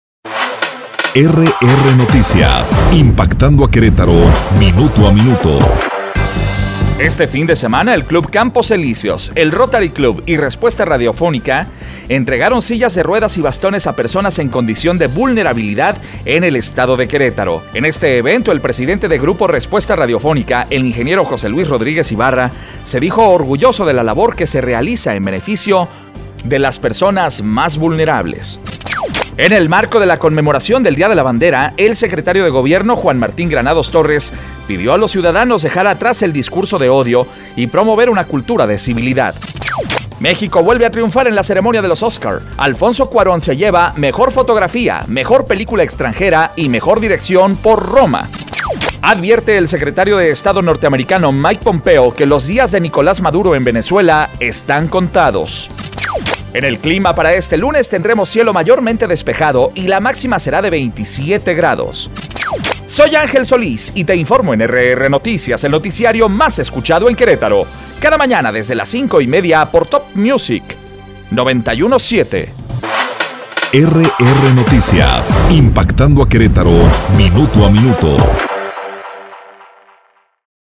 Resumen Informativo 25 de febrero 2019